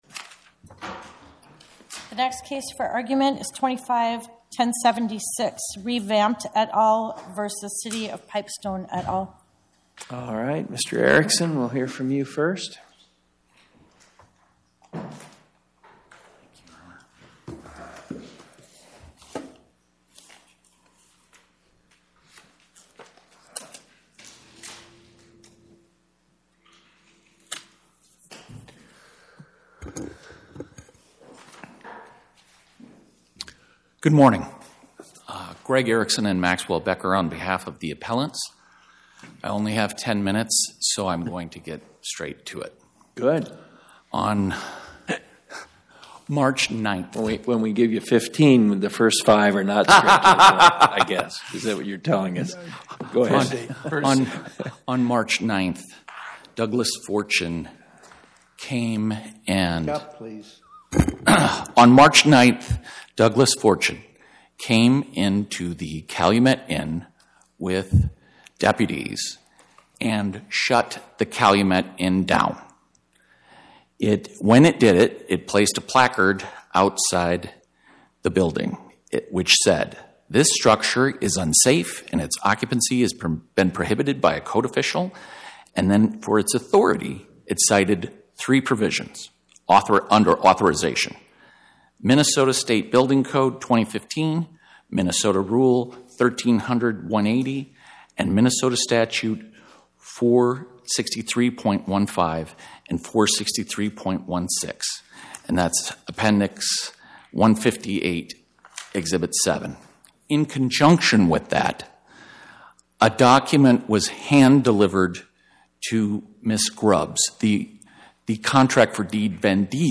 My Sentiment & Notes 25-1076: reVamped LLC vs City of Pipestone Podcast: Oral Arguments from the Eighth Circuit U.S. Court of Appeals Published On: Wed Oct 22 2025 Description: Oral argument argued before the Eighth Circuit U.S. Court of Appeals on or about 10/22/2025